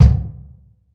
KICK1072.WAV